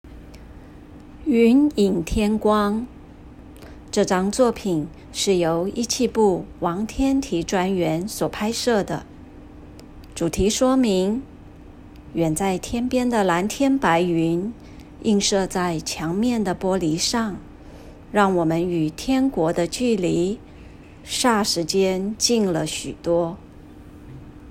語音導覽-19雲影天光.m4a